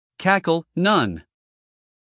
英音/ ˈkækl / 美音/ ˈkækl /
wordmp3cackle_mp3.mp3